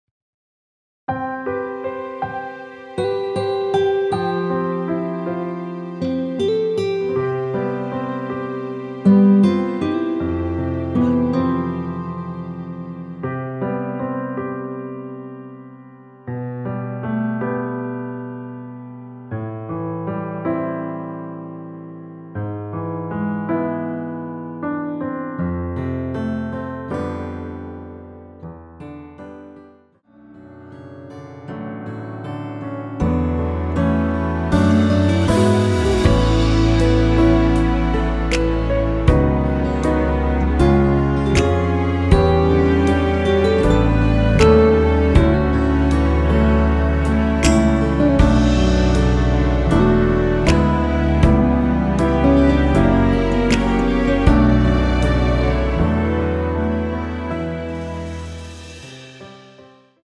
원키에서(-4)내린 MR입니다.
앞부분30초, 뒷부분30초씩 편집해서 올려 드리고 있습니다.
중간에 음이 끈어지고 다시 나오는 이유는